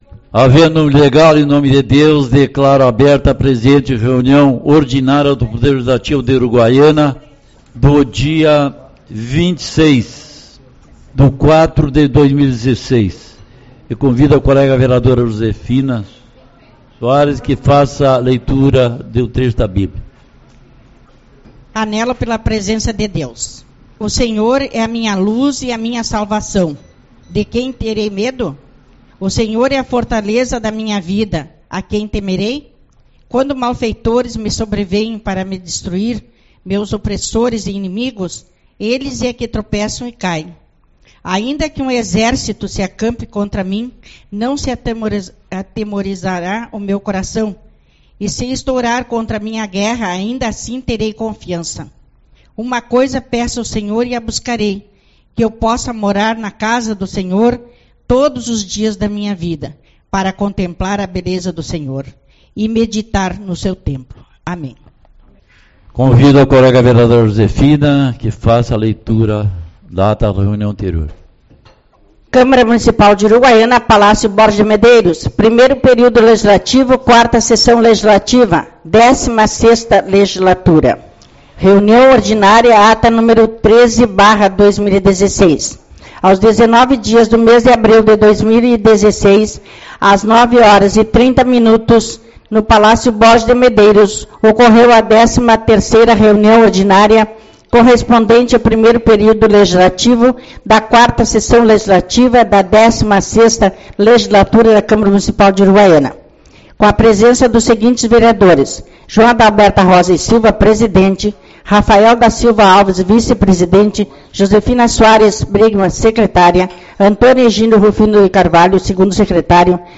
26/04 - Reunião Ordinária